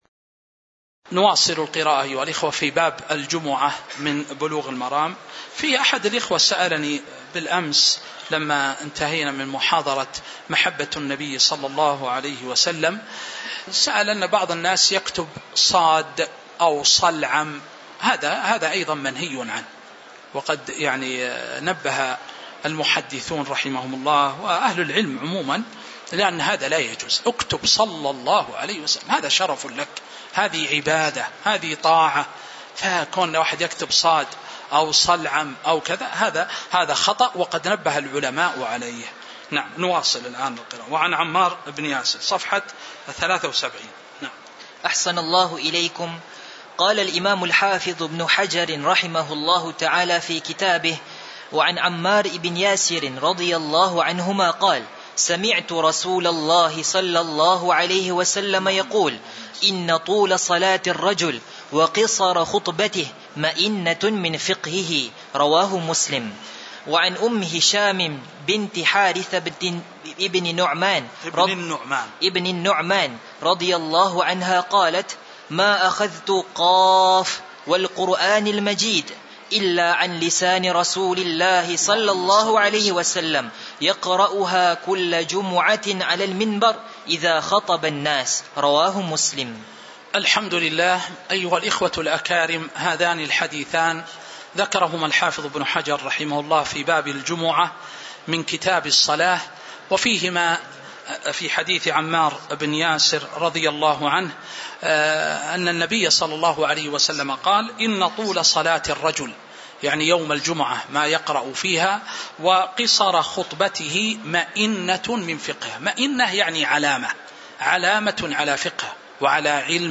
تاريخ النشر ١٦ رجب ١٤٤٥ هـ المكان: المسجد النبوي الشيخ